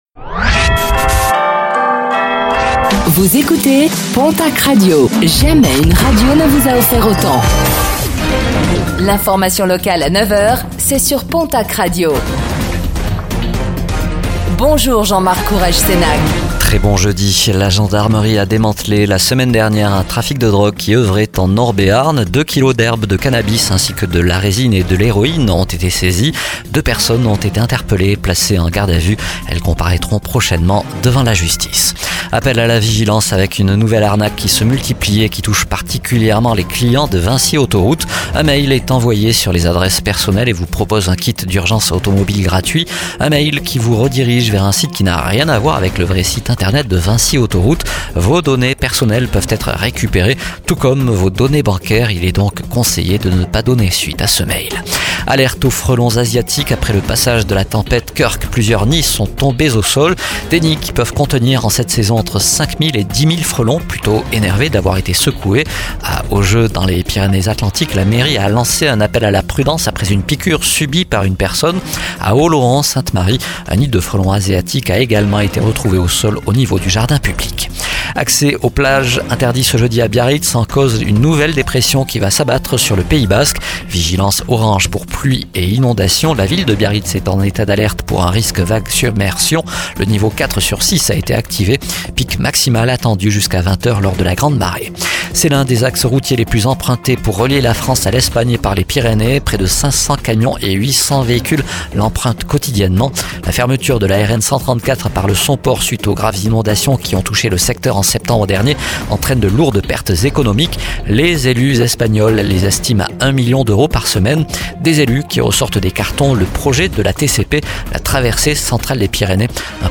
09:05 Écouter le podcast Télécharger le podcast Réécoutez le flash d'information locale de ce jeudi 17 octobre 2024